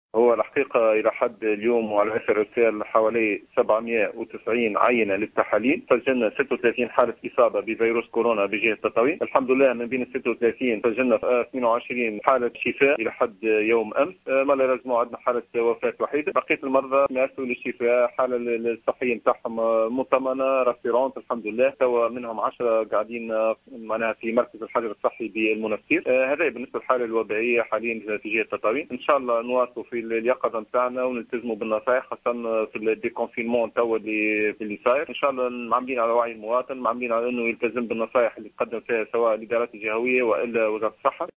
و أضاف غرغار في تصريح للجوهرة أف أم ، أنه تمّ منذ بداية جائحة كورونا، رفع حوالي 790 عيّنة لأشخاص يُشتبه في إصابتهم بفيروس كورونا، 36 منها تحاليل إيجابية، مع تسجيل حالة وفاة.